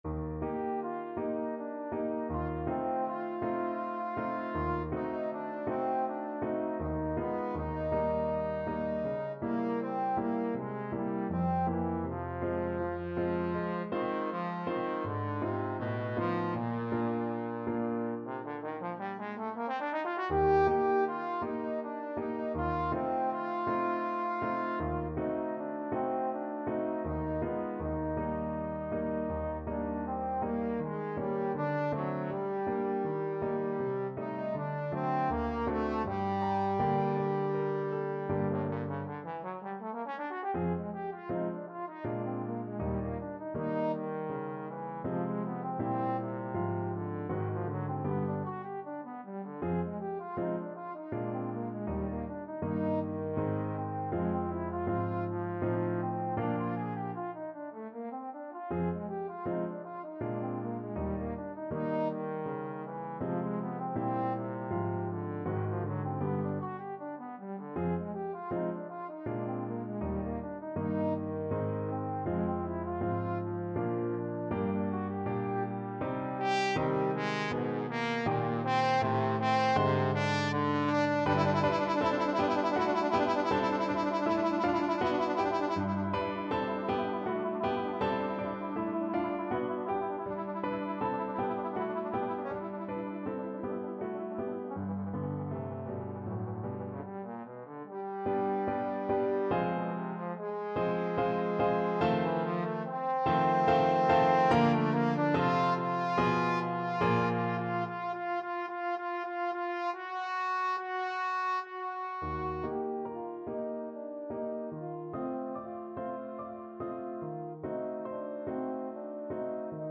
Moderato con moto =80
3/4 (View more 3/4 Music)
Classical (View more Classical Trombone Music)